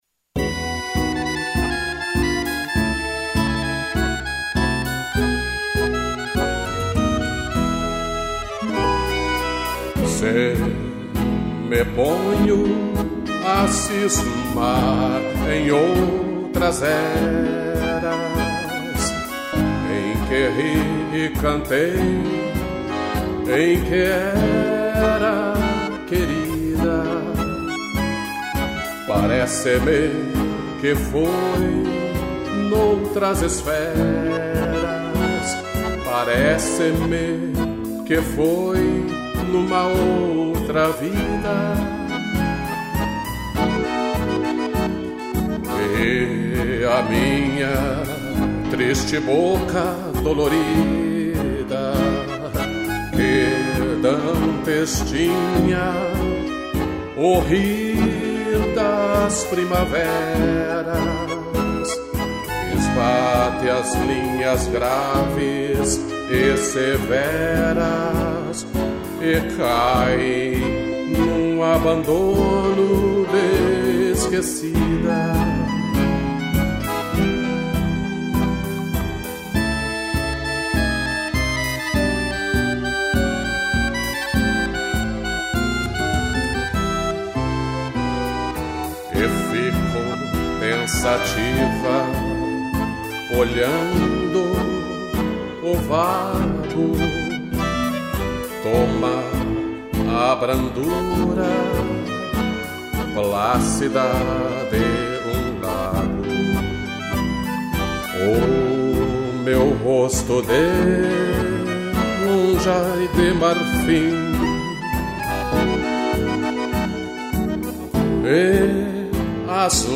tango
acordeon base e violino